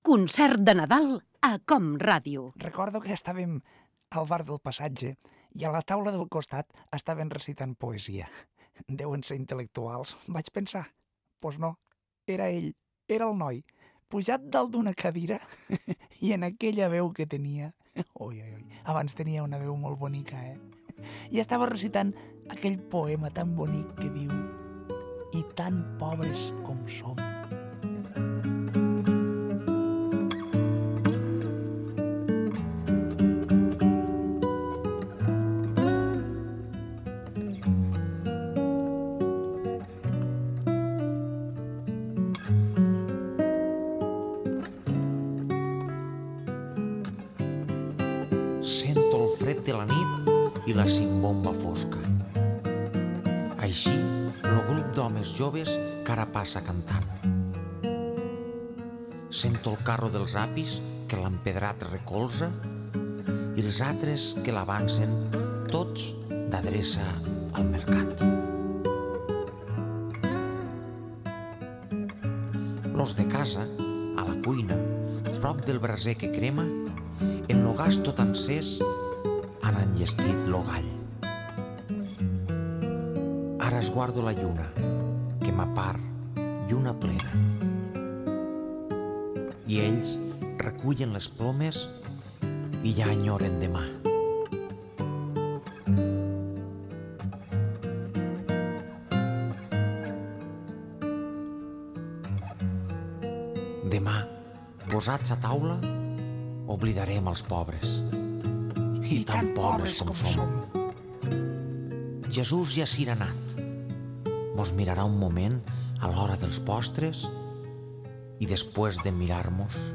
Inici del programa i fragment d'una entrevista al grup Quico el Celio, el noi i el mut de Ferreries.
Musical